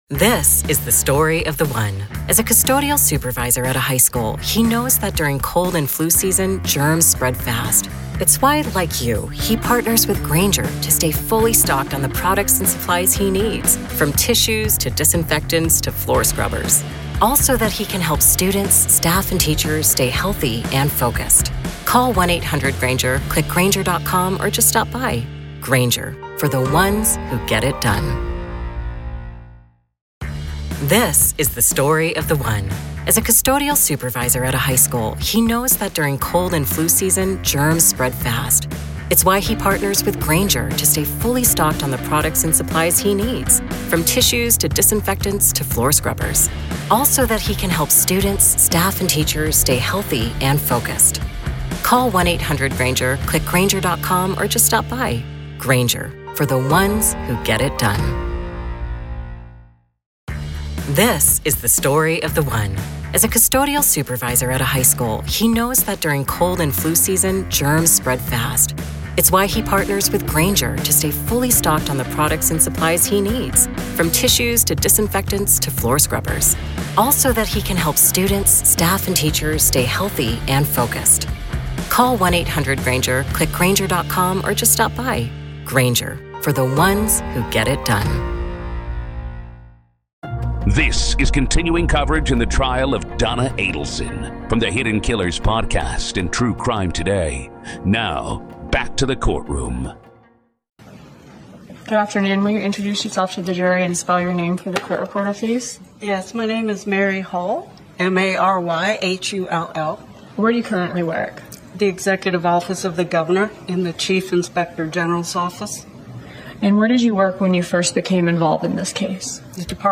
delivered detailed financial testimony